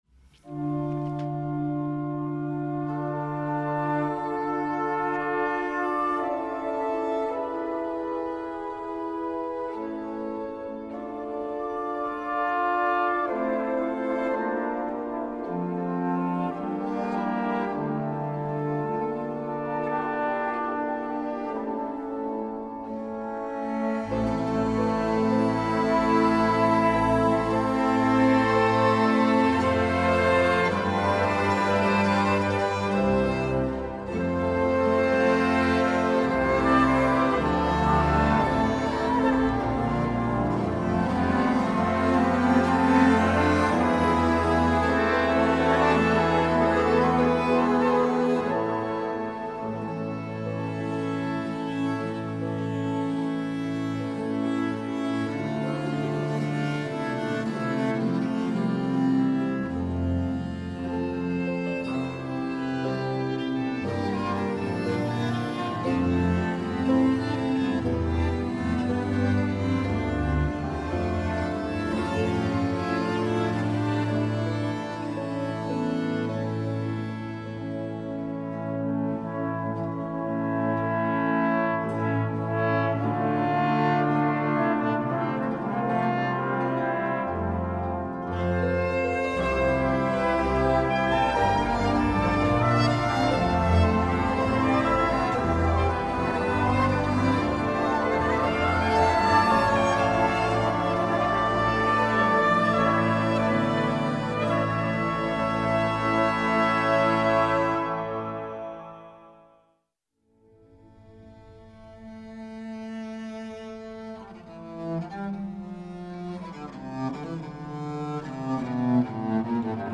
Opening van deze Paaszondag met muziek, rechtstreeks vanuit onze studio.